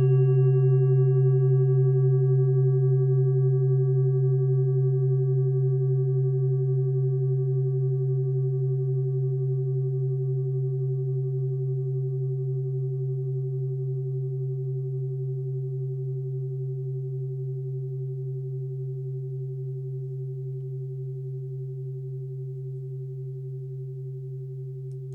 Klangschale TIBET Nr.42
(Ermittelt mit dem Filzklöppel)
klangschale-tibet-42.wav